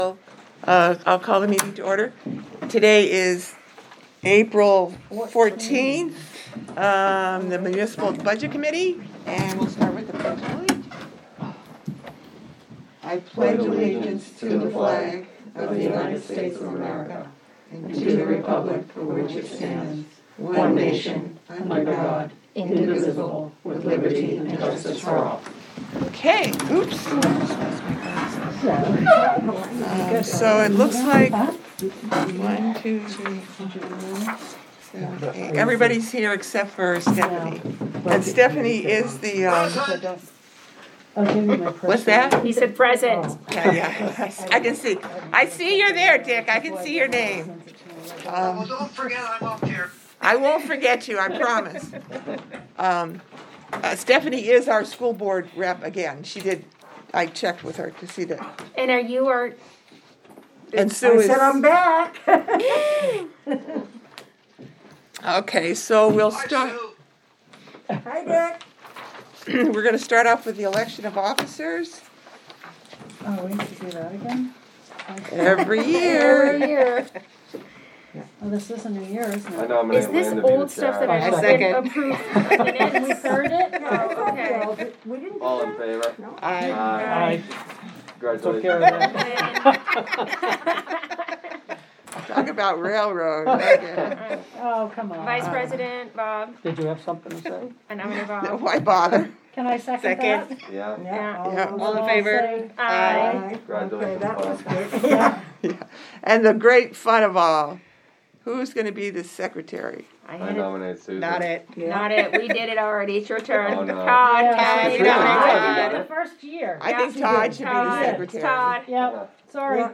Audio recordings of committee and board meetings.